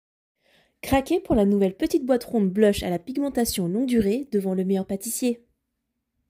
Bandes-son
Voix off
10 - 40 ans - Mezzo-soprano